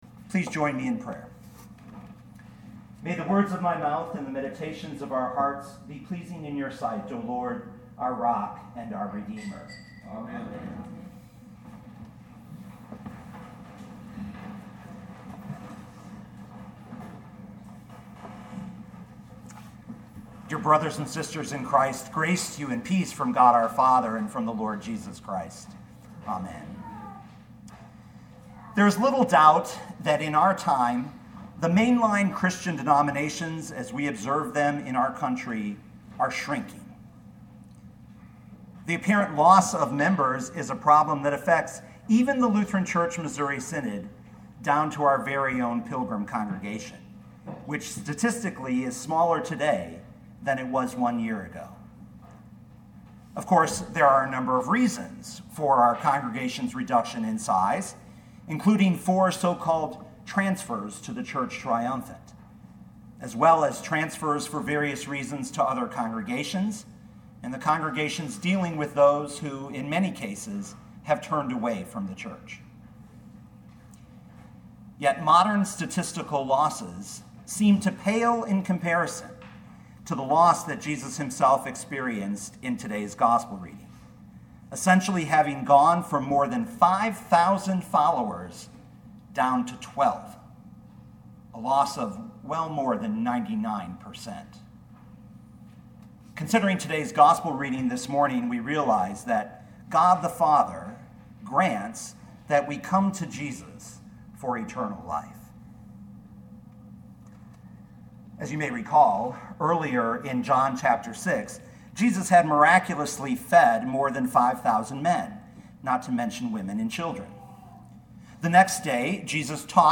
2018 John 6:51-69 Listen to the sermon with the player below, or, download the audio.